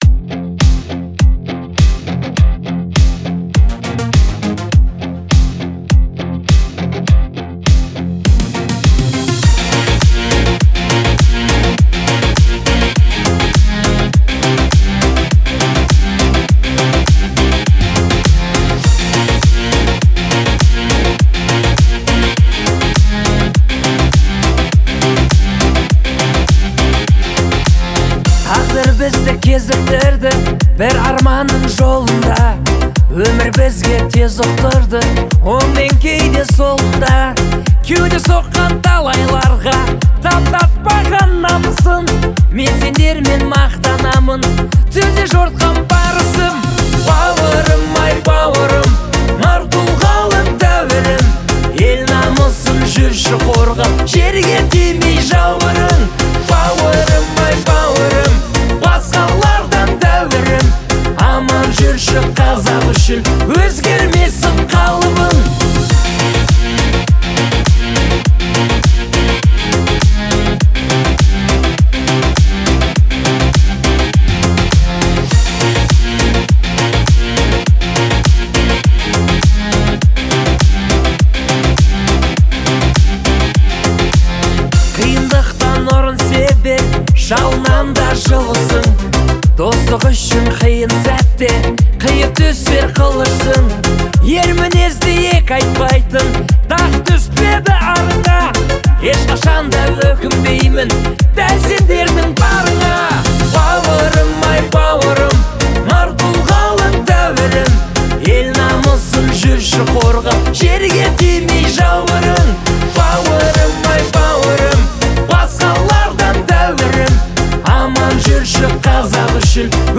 это трогательная песня в жанре казахского поп-фолка